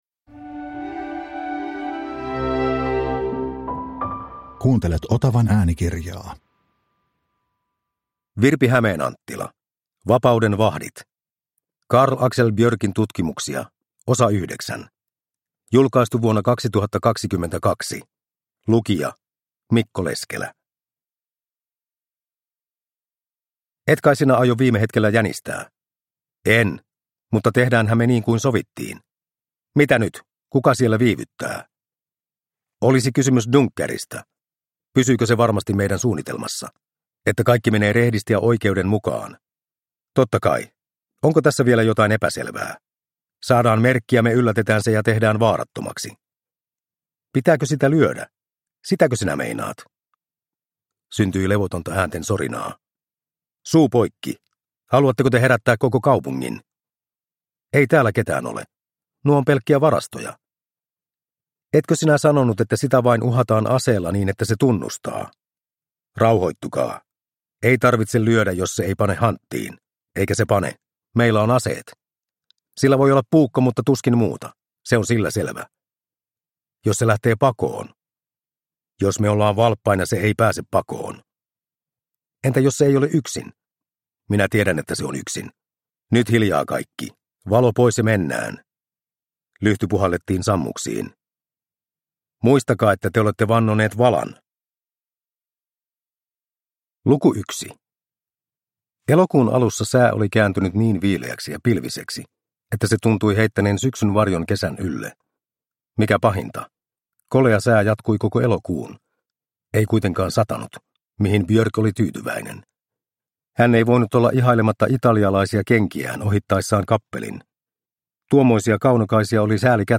Vapauden vahdit – Ljudbok – Laddas ner